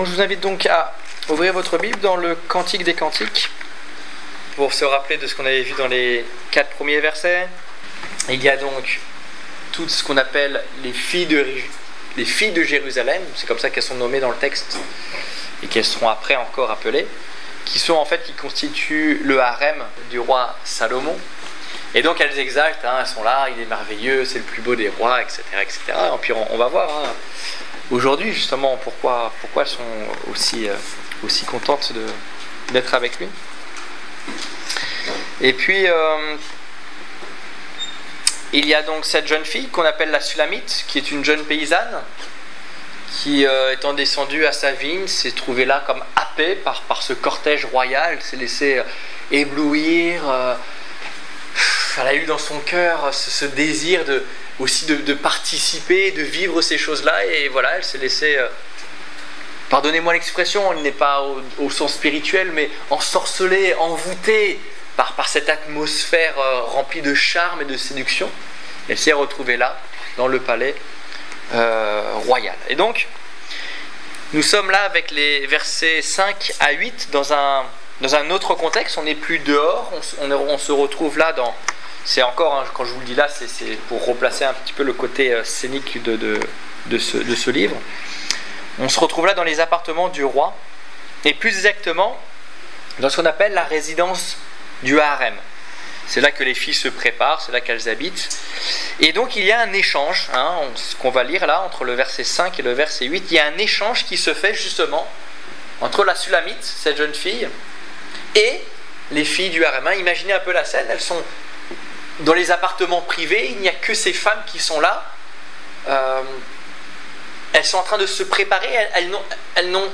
Étude biblique du 1 juillet 2015